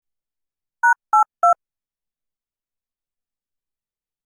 該当の項目をクリックすると、電話のプッシュ音のような音が流れます。